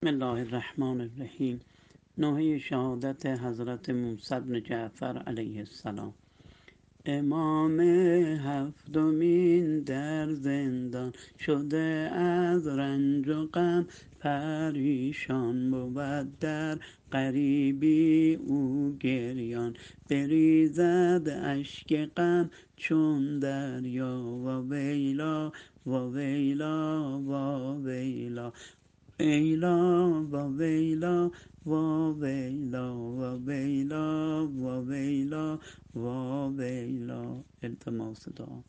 نوحه شهادت حضرت موسی بن جعفر علیه السلام -(امام هفتمین در زندان )